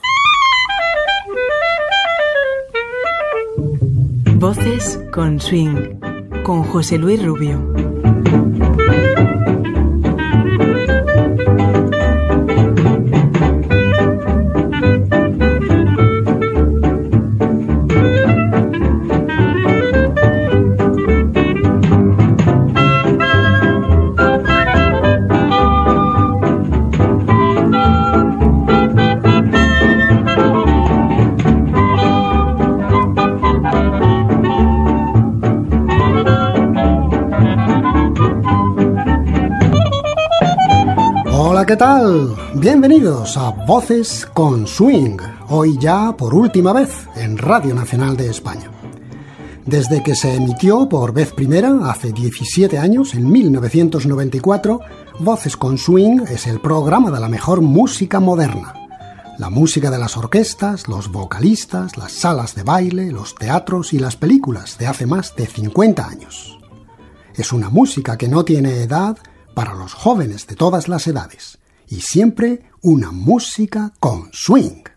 Careta del programa i presentació de la seva última edició.
Musical
FM